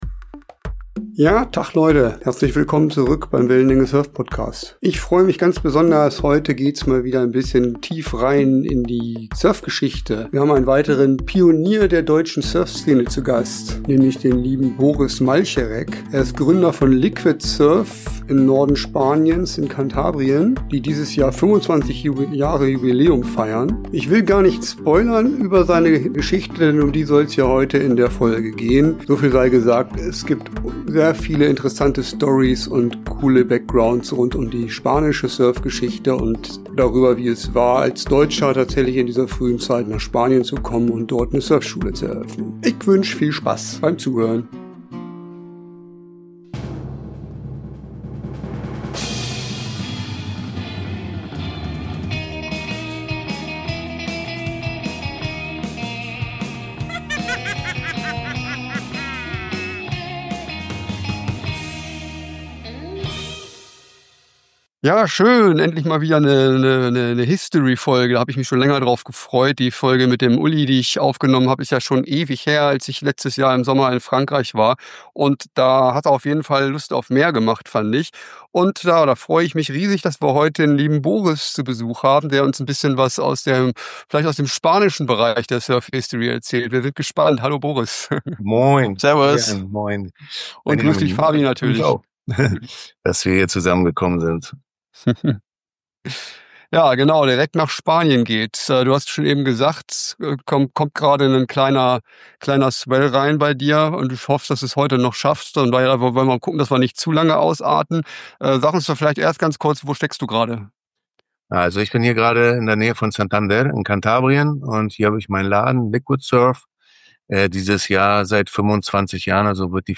Dabei geht es auch um prägende Stationen wie Bali und Barbados – Geschichten aus einer Zeit, als das Surfen noch deutlich anders aussah. Gemeinsam schlagen wir den Bogen zur Gegenwart und reflektieren, was sich über die Jahre verändert hat – zum Guten wie zum Schlechten. Ein Gespräch voller spannender Anekdoten, historischer Einblicke und ehrlicher Gedanken zur Entwicklung des Surfens.